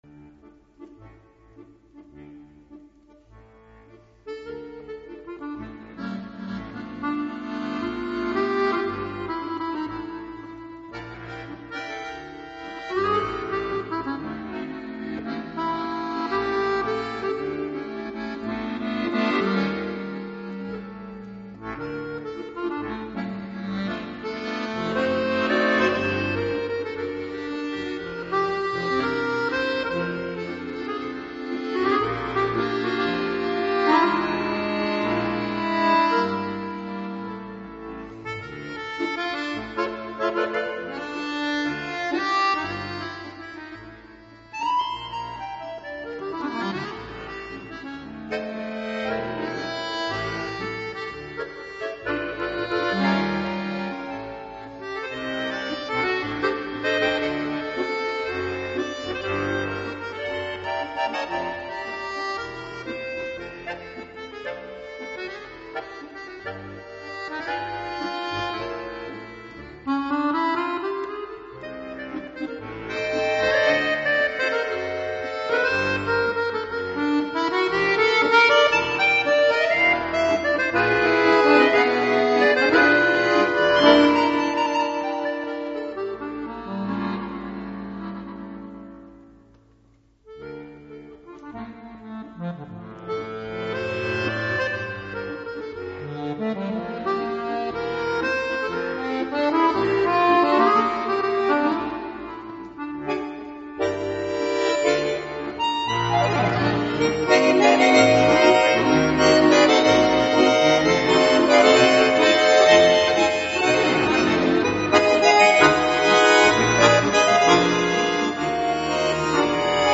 С музыкой лёгкая грусть наплывает...